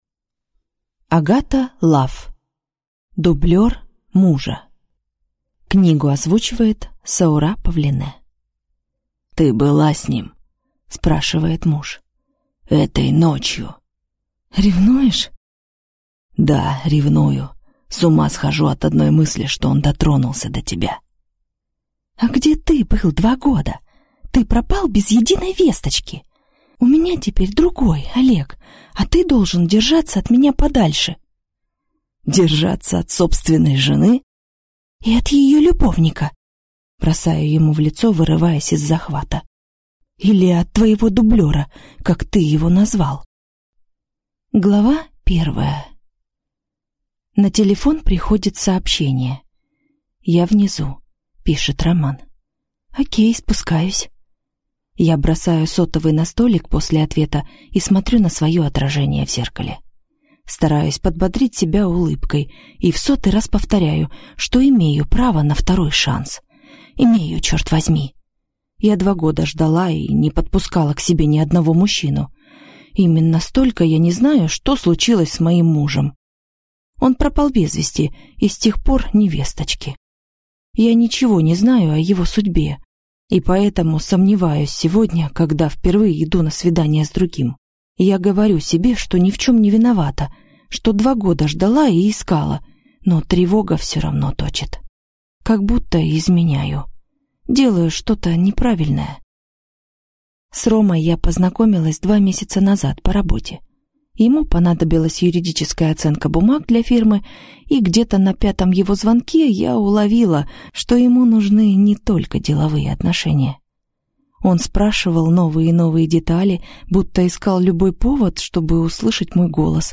Аудиокнига Дублер мужа | Библиотека аудиокниг